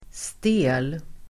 Uttal: [ste:l]